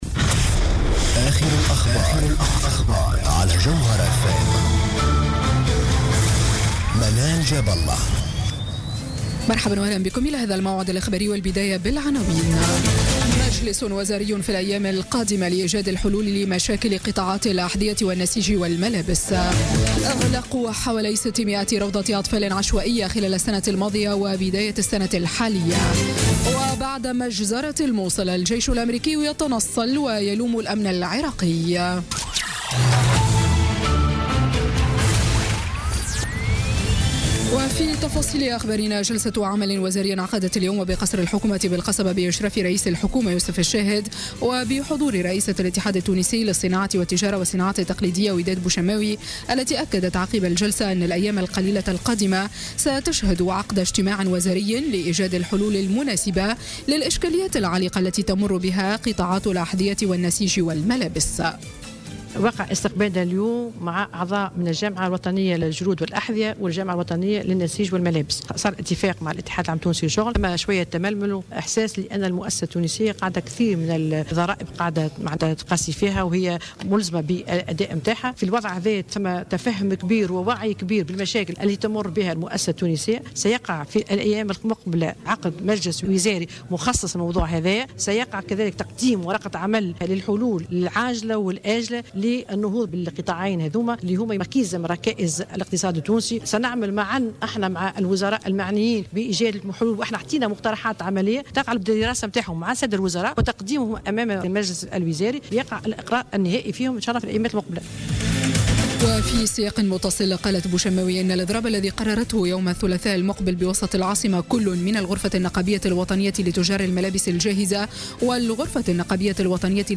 نشرة أخبار السابعة مساء ليوم السبت 25 مارس 2017